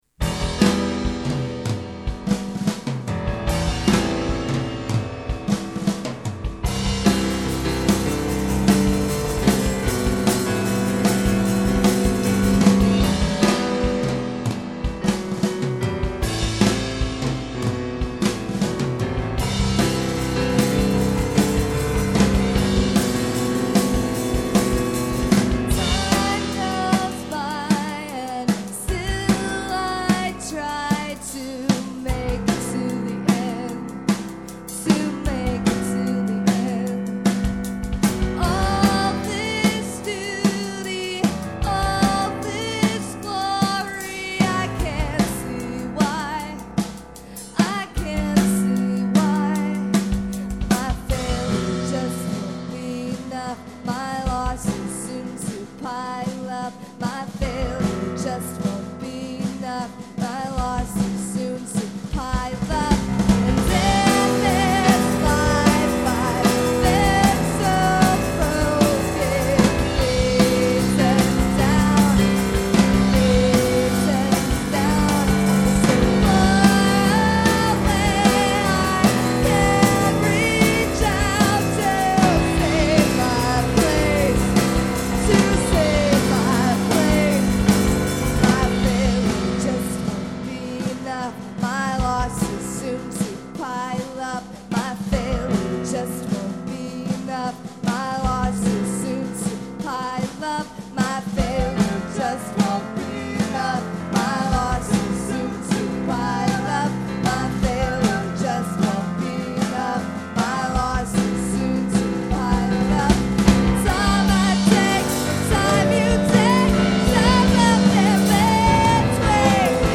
Songs 11-18 recorded live in Olympia, WA, summer 2002.